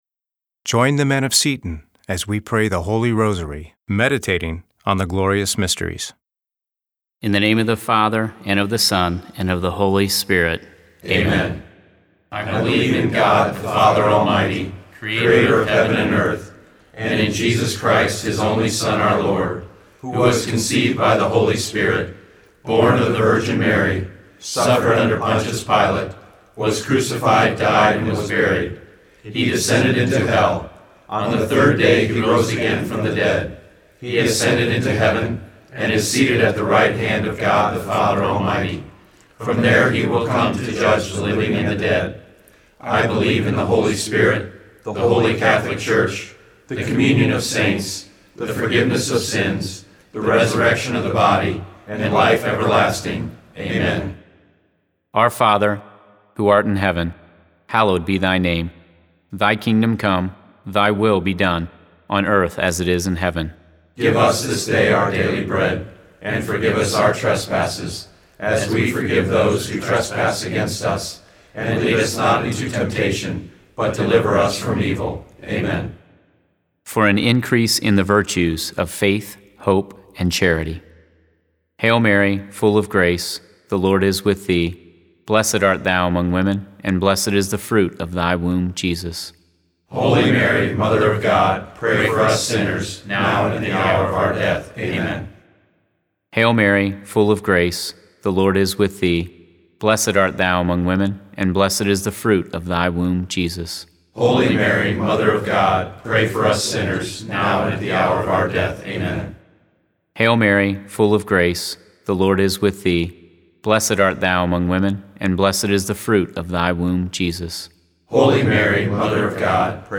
Click here to listen to the full Rosary, prayed by the men of Seton: The Joyful Mysteries The Luminous Mysteries The Sorrowful Mysteries The Glorious Mysteries Seton is a parish that prays for each other.
RosaryGlorious_Mysteries_Fina.mp3